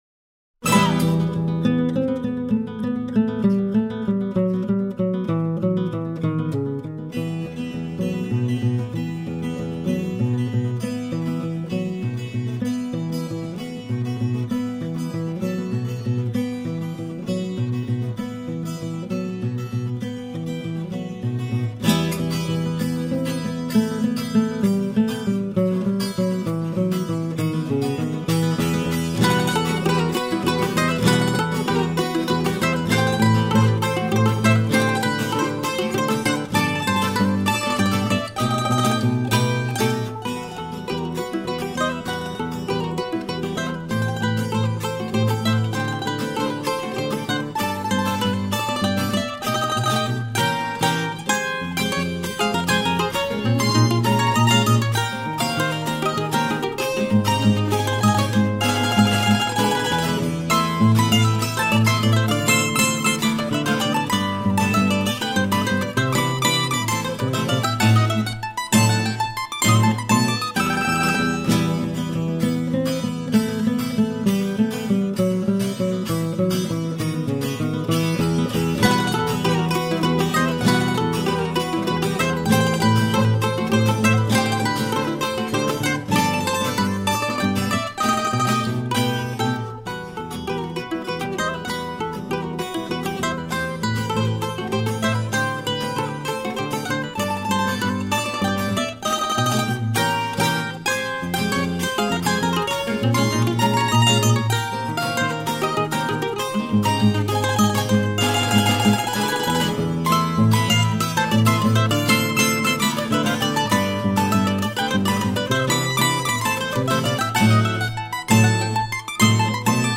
Bambuco
Cuarteto Instrumental
Colombiana